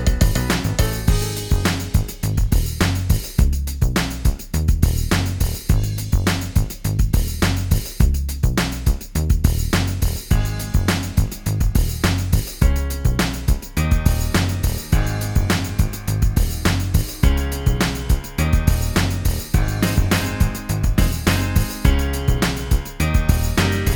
Minus All Guitars Indie / Alternative 3:26 Buy £1.50